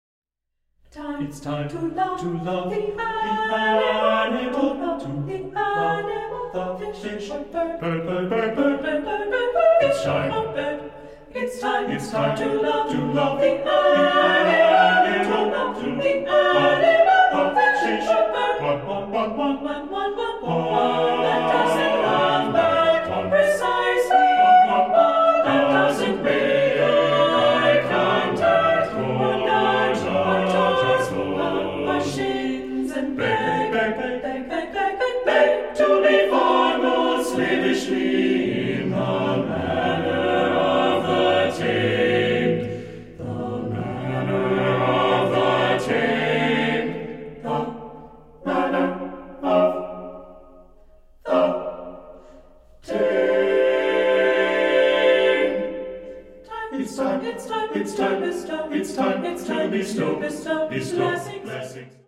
• Genres: Choral Music